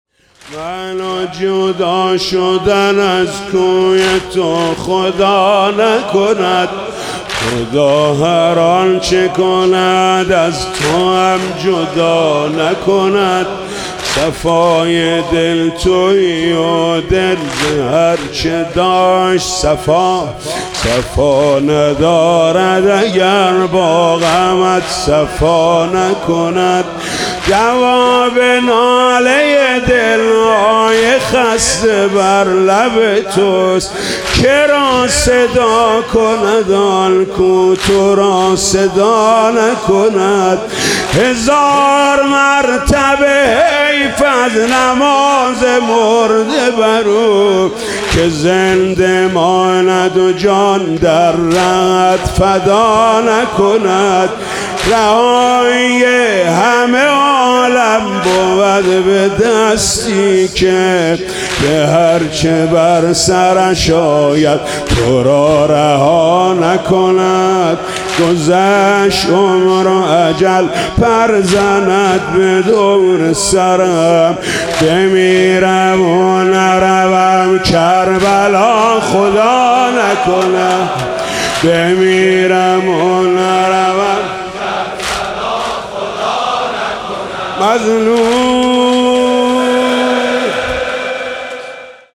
مداحی واحد